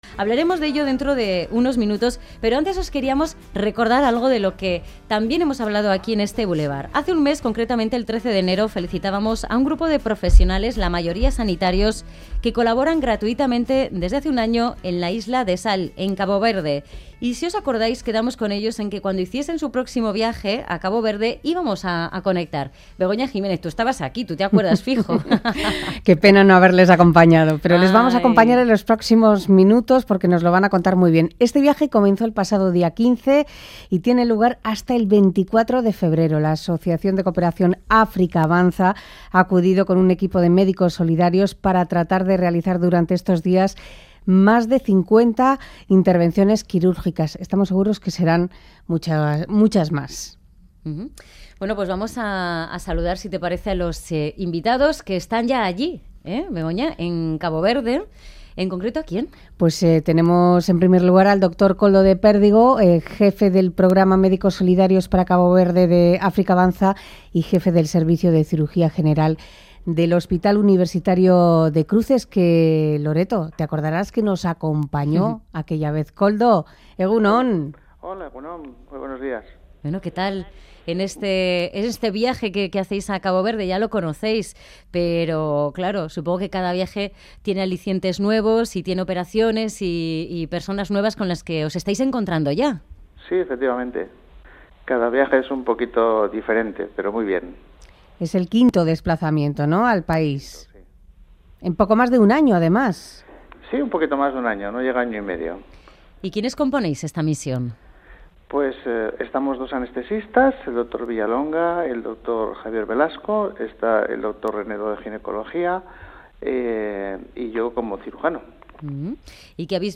Conectamos en directo con el Hospital Público de Sal en la localidad de Espargos en Cabo Verde